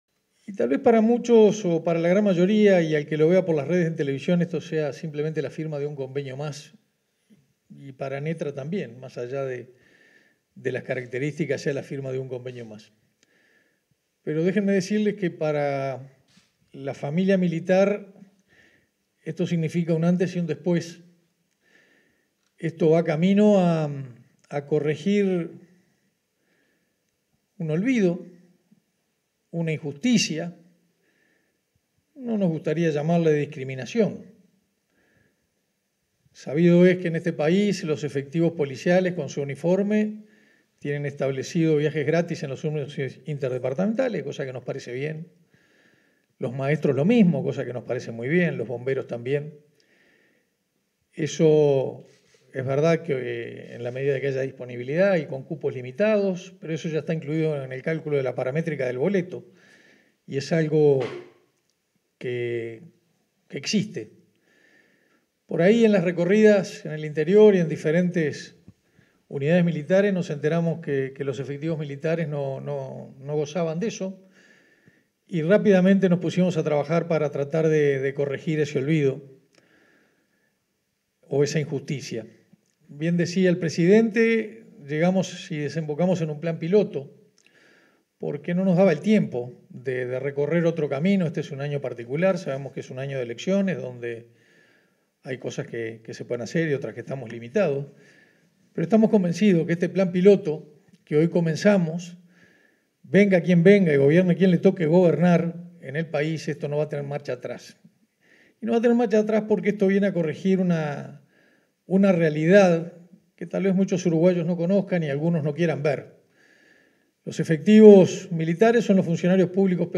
Palabras de los ministros de Transporte y Obras Públicas y de Defensa Nacional
En el marco de un convenio por el cual se otorgan pasajes de transporte colectivo a efectivos militares que revistan funciones en unidades en un departamento diferente a donde resida su familia, este 27 de agosto, se expresaron los ministros de Transporte y Obras Públicas, José Luis Falero, y de Defensa Nacional, Armando Castaingdebat.